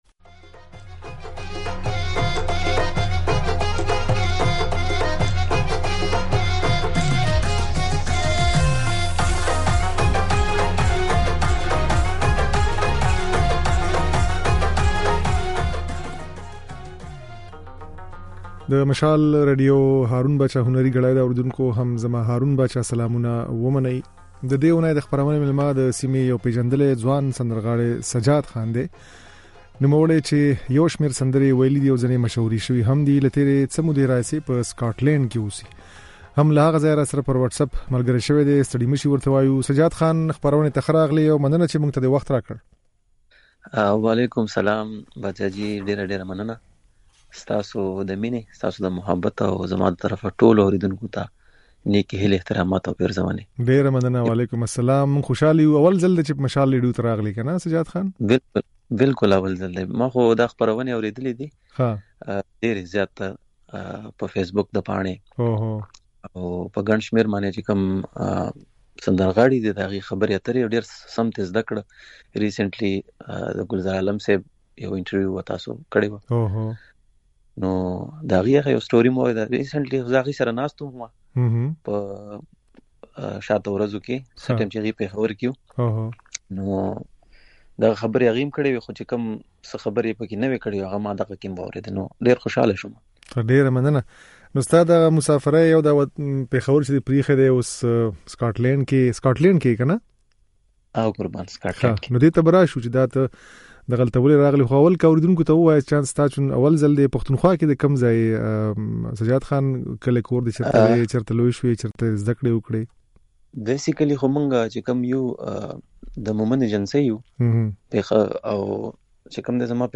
د نوموړي خبرې او ځينې سندرې يې په خپرونه کې اورېدای شئ.